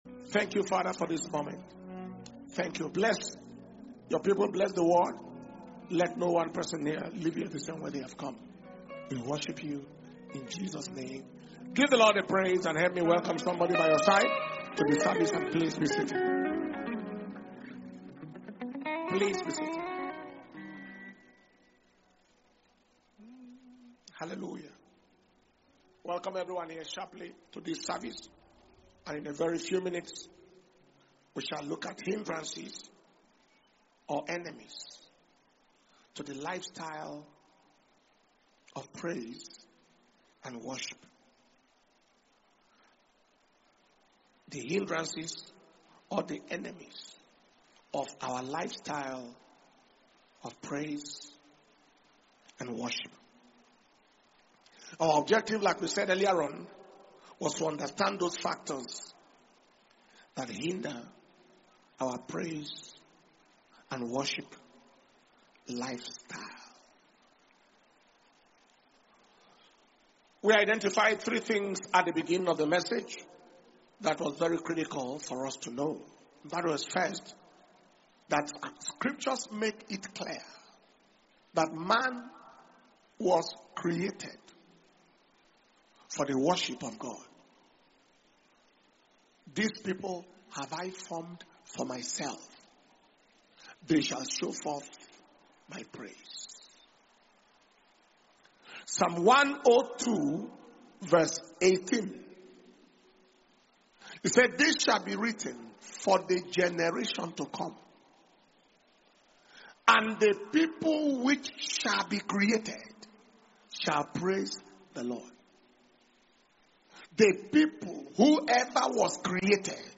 June 2025 Testimony And Thanksgiving Service